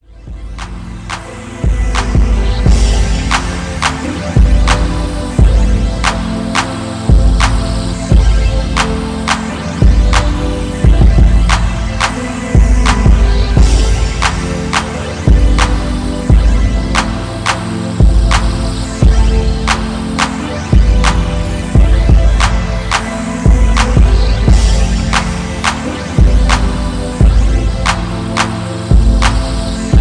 Hip hop with a smooth flow